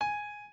piano9_7.ogg